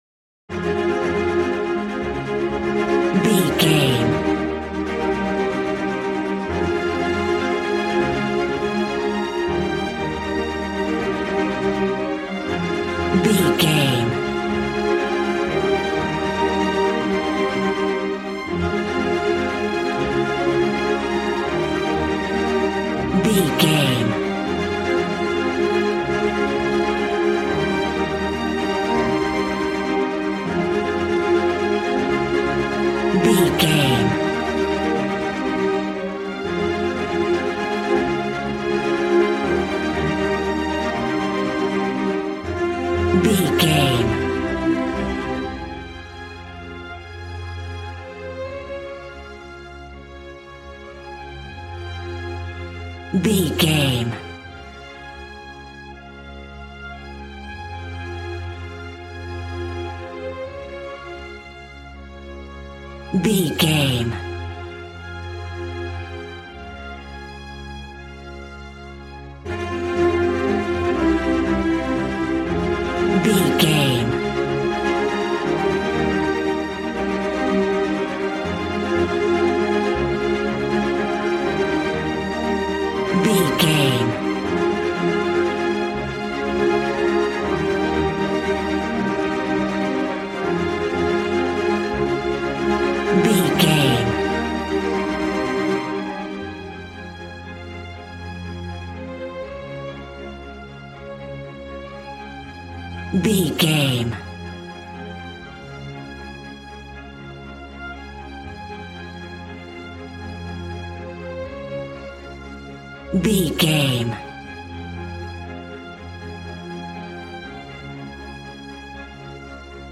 Regal and romantic, a classy piece of classical music.
Aeolian/Minor
regal
strings
brass